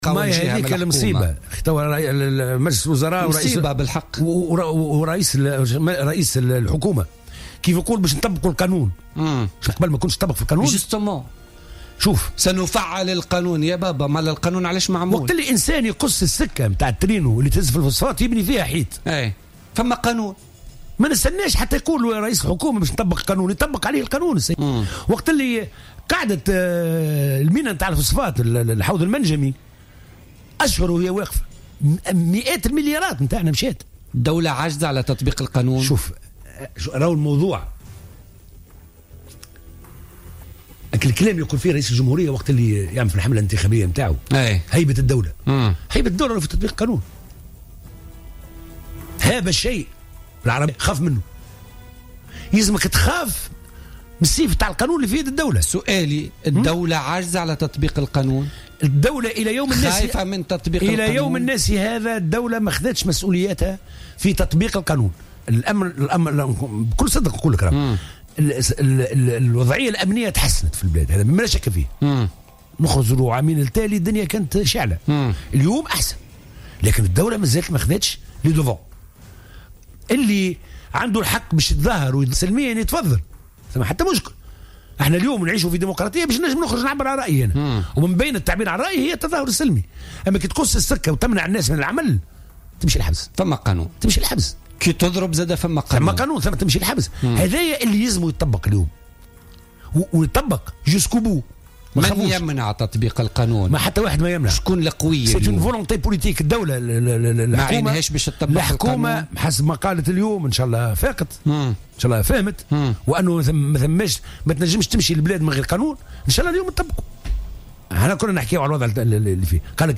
قال عبادة الكافي النائب بمجلس نواب الشعب عن كتلة الحرة وضيف برنامج بوليتكا لليوم الخميس 31 مارس 2016 إن الدولة لم تتحمل إلى يومنا هذا مسؤوليتها في تطبيق القانون.